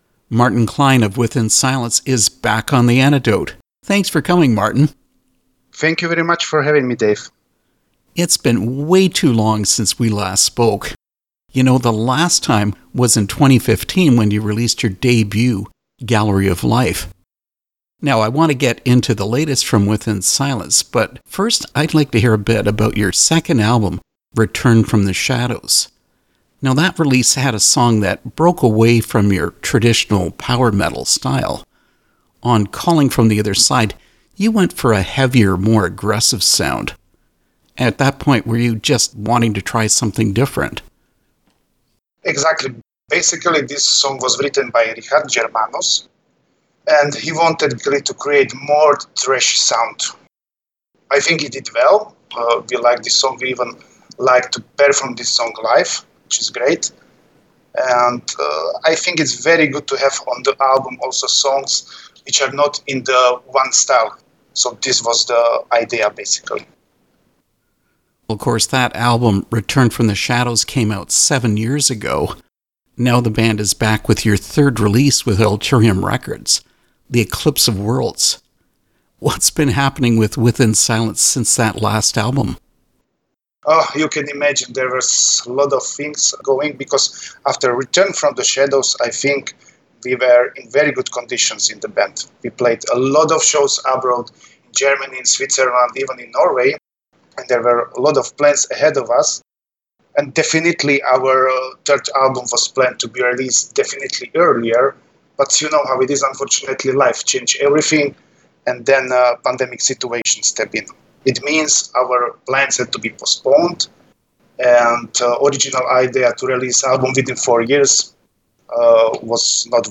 Interview with Within Silence 2024
within-silence-interview-2024.mp3